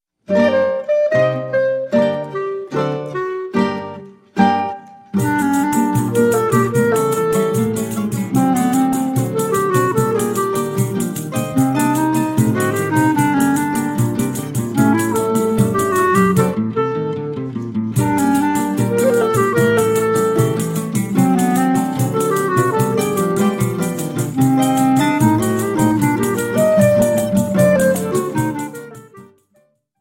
accompanied by a Choro Ensemble.
clarinet